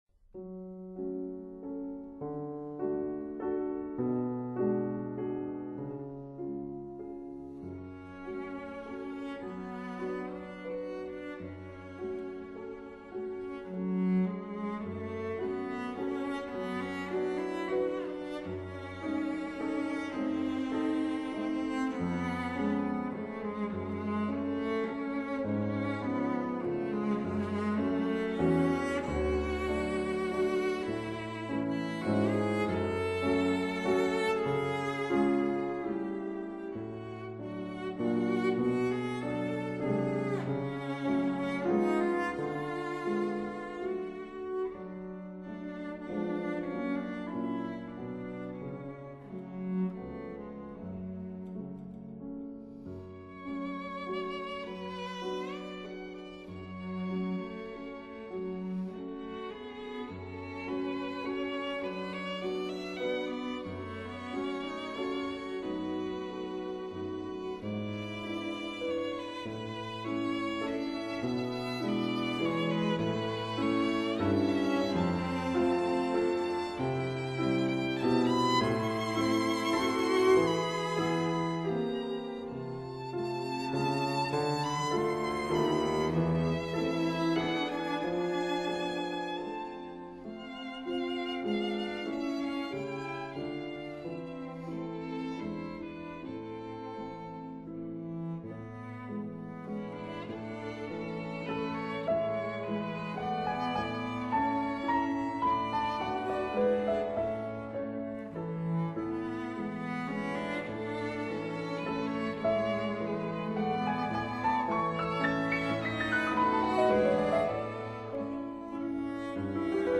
piano
violin
cello